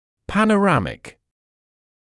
[ˌpænə’ræmɪk][ˌпэнэ’рэмик]панорамный